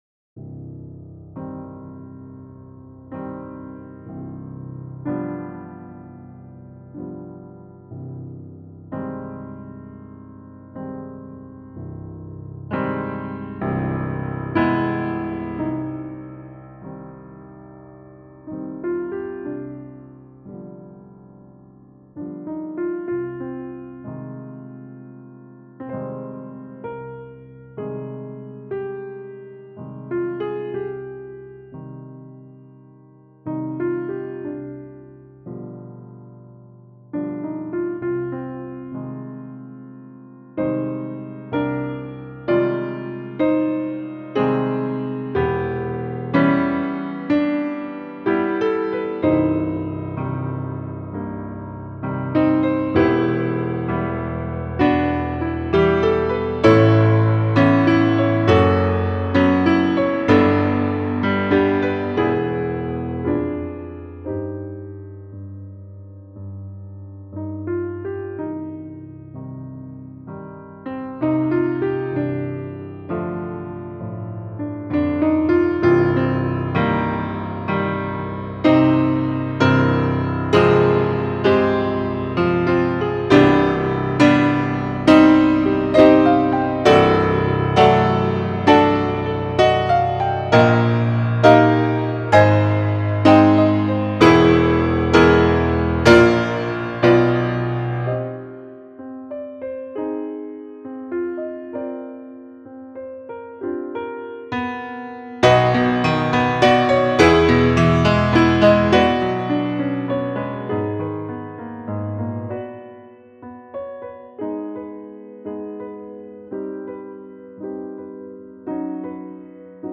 Anguish and despair in the ruins of the past      mp3